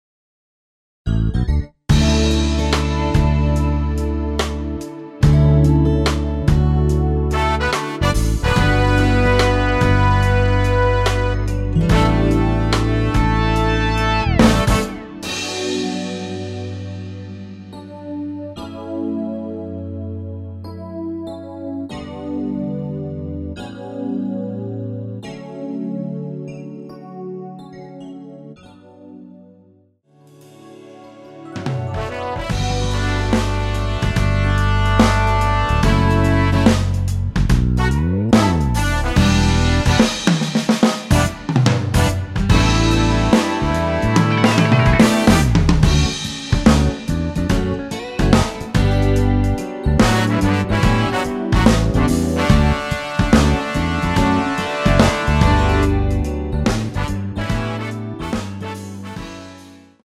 앨범 | O.S.T
◈ 곡명 옆 (-1)은 반음 내림, (+1)은 반음 올림 입니다.
앞부분30초, 뒷부분30초씩 편집해서 올려 드리고 있습니다.